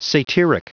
Prononciation du mot satyric en anglais (fichier audio)
Prononciation du mot : satyric